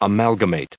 38_amalgamate.ogg